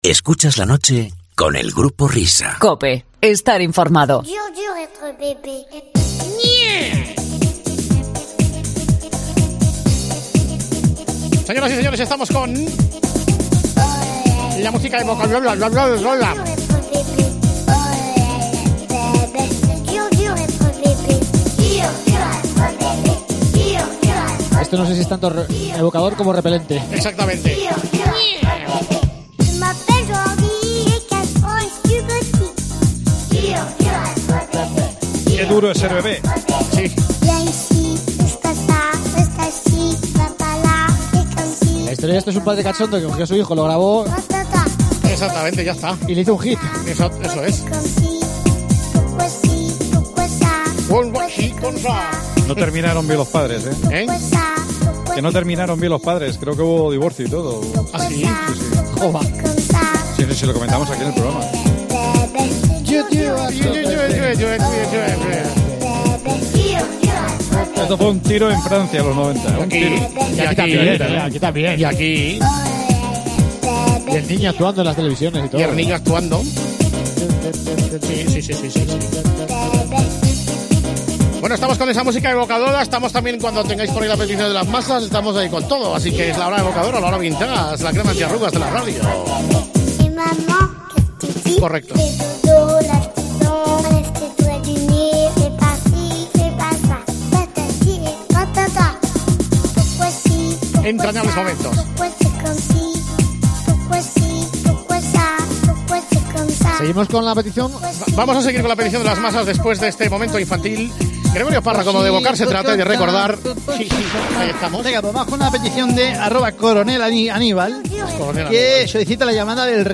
La broma a Blatter y música vintage con el Grupo Risa